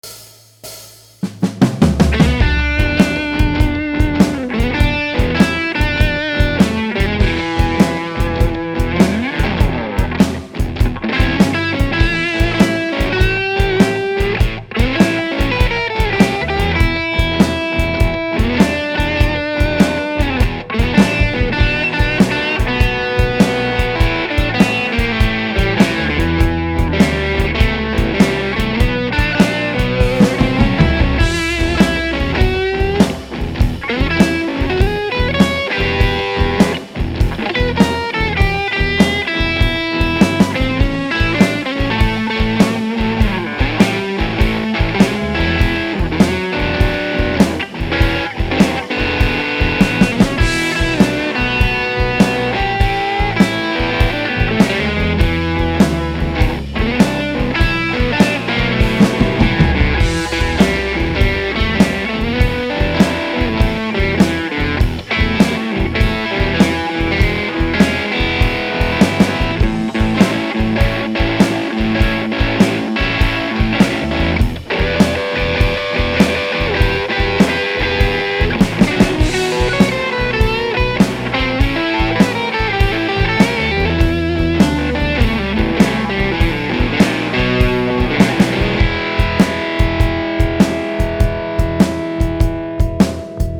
Style: Shuffle Blues